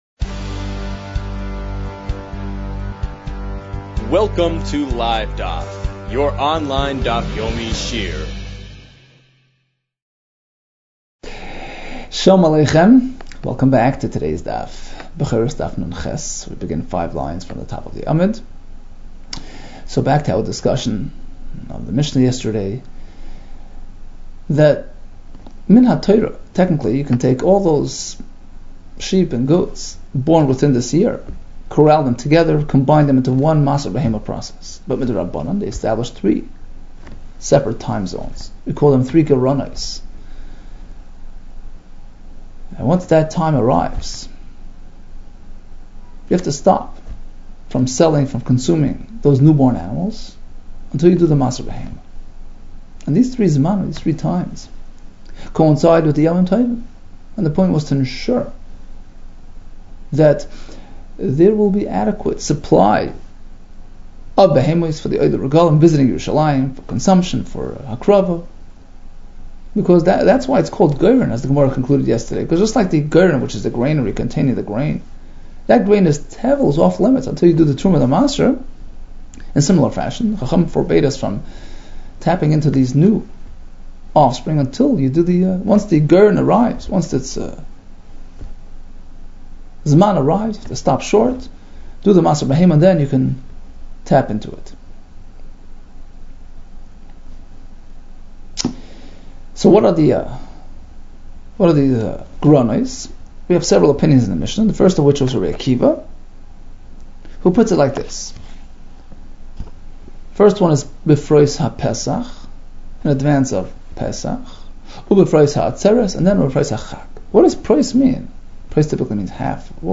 Bechoros 58 - בכורות נח | Daf Yomi Online Shiur | Livedaf